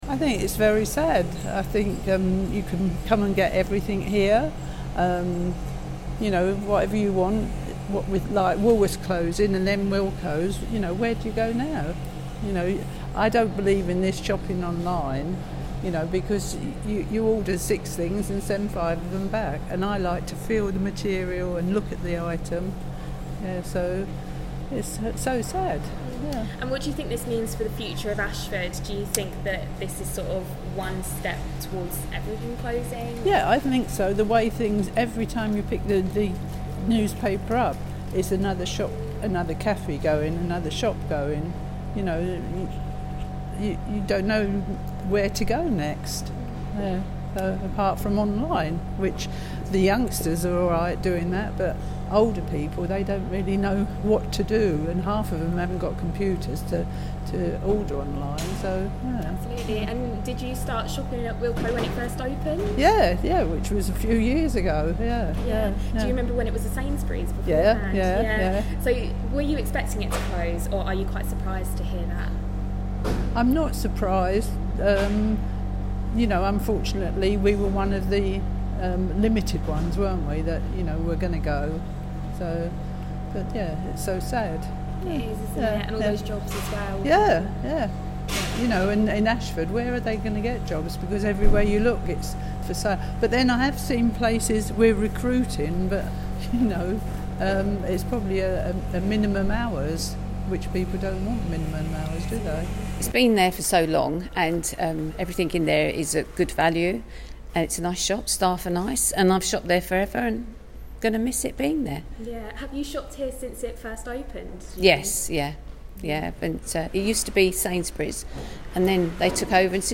Listen: Customers react to the news four Wilko stores will be closing.